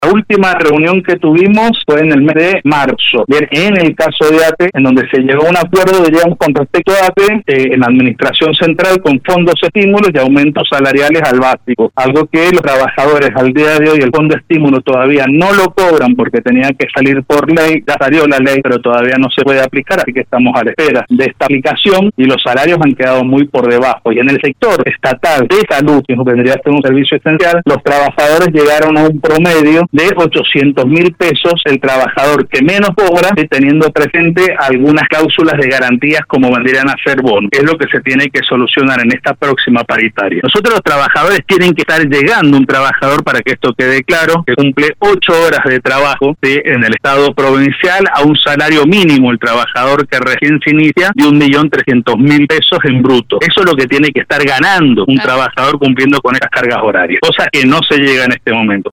en una entrevista concedida a LV18 que desnuda la profundidad del colapso social que atraviesan los trabajadores estatales.